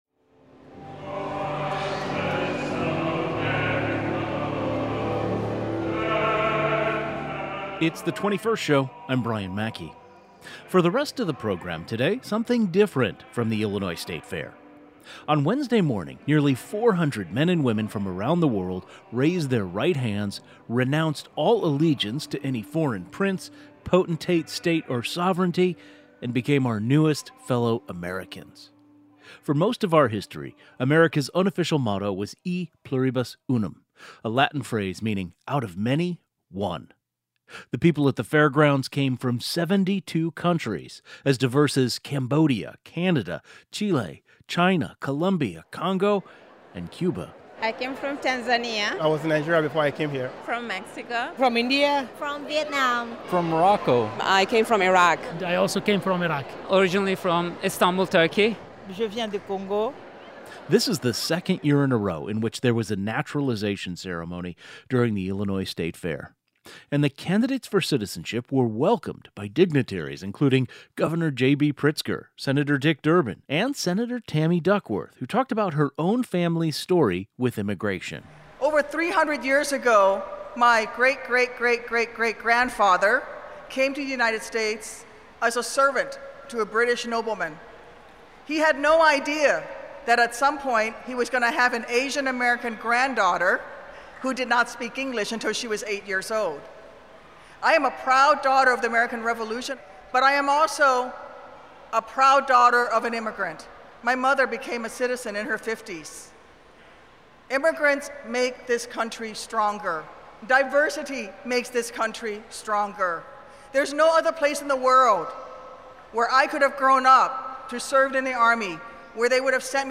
Naturalization ceremony at Illinois State Fair celebrates immigrants